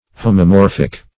Search Result for " homomorphic" : The Collaborative International Dictionary of English v.0.48: Homomorphic \Ho`mo*mor"phic\, Homomorphous \Ho`mo*mor"phous\, a. [Gr.